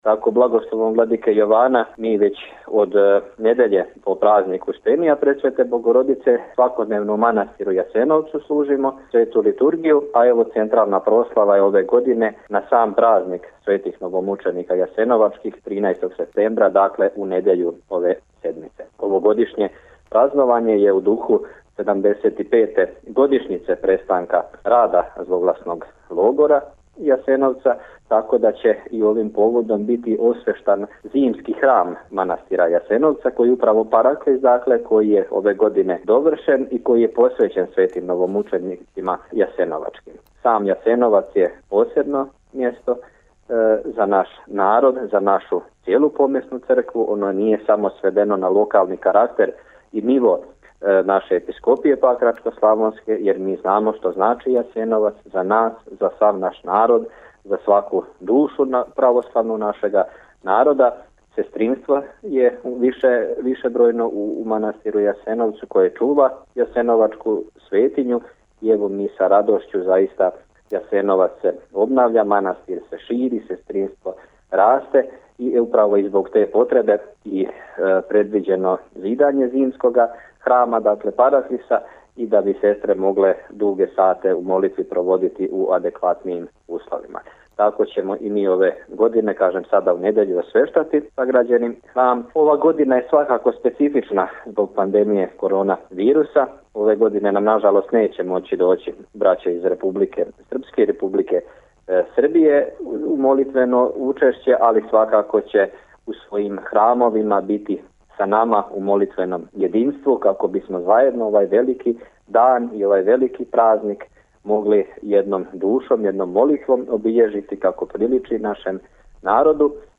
Звучни запис разговора